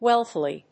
音節wealth･i･ly発音記号・読み方wélθɪli